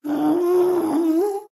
latest / assets / minecraft / sounds / mob / ghast / moan5.ogg
moan5.ogg